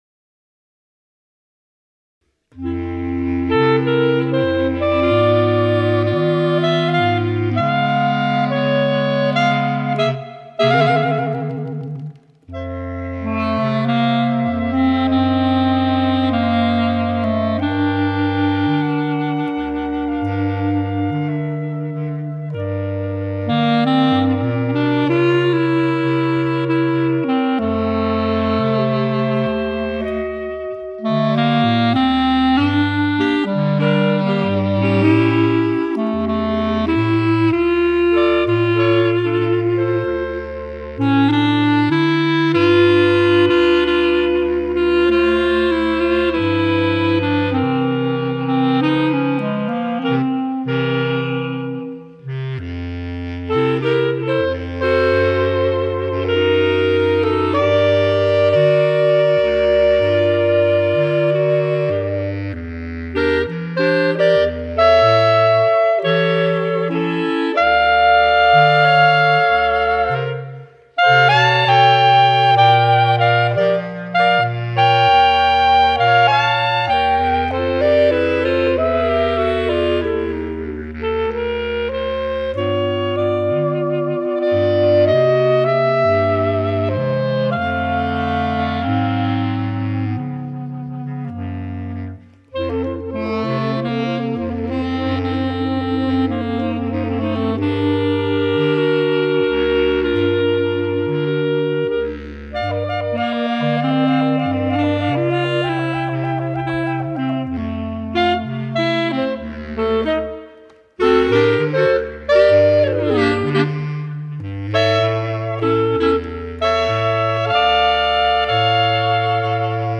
The rumba styled original
Rumba